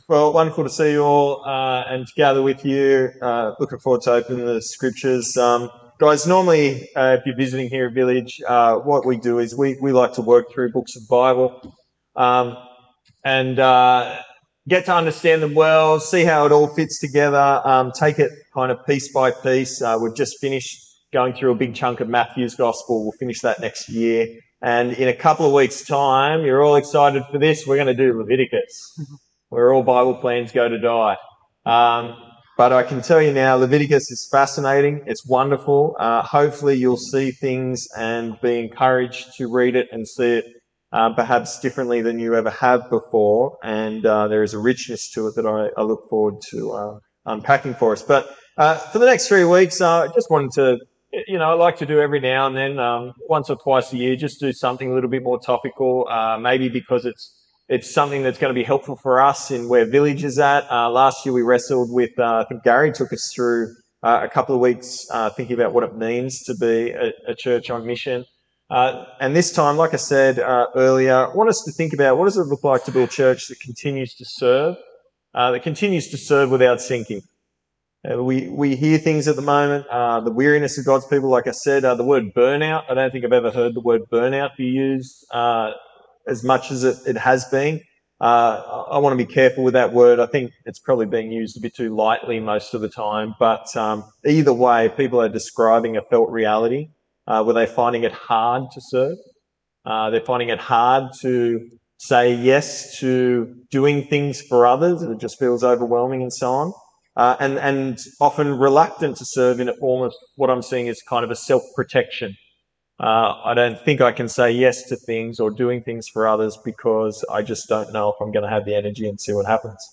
Village Church Home I'm New Who is Jesus Sermons Part 1 May 4, 2025